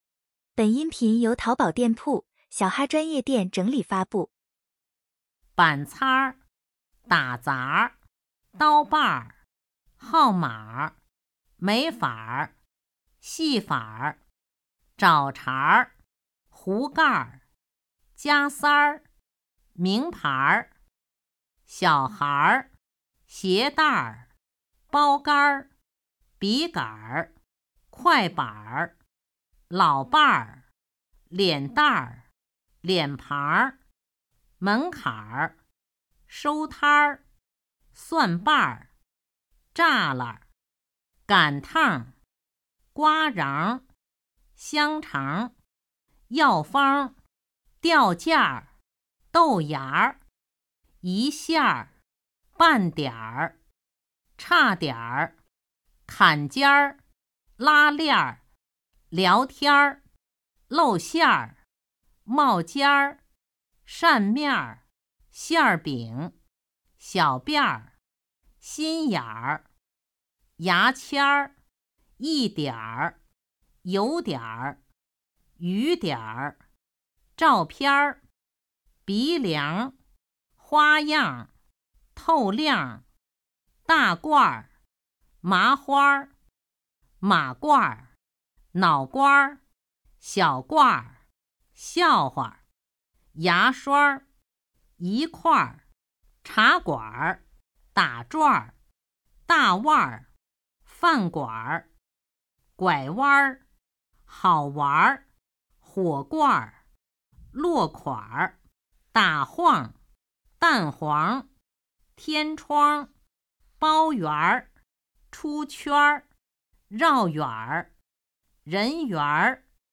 儿化音1到100音频.mp3